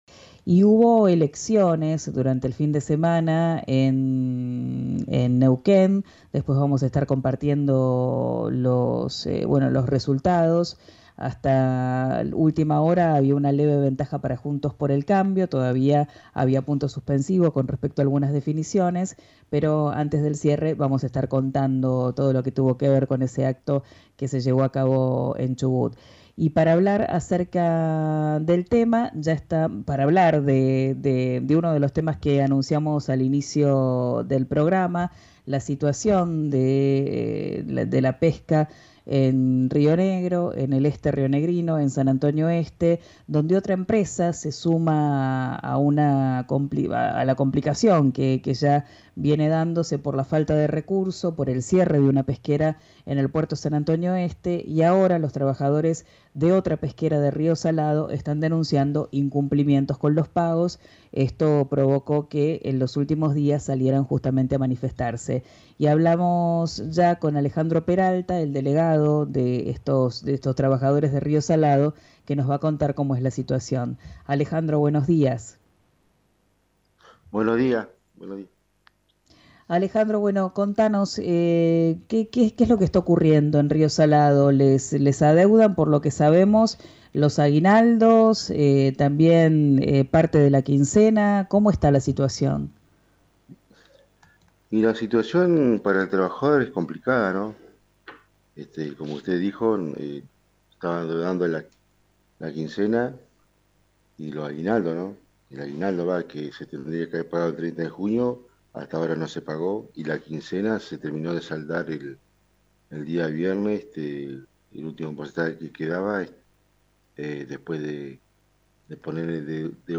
en diálogo con «Arranquemos», por RÍO NEGRO RADIO.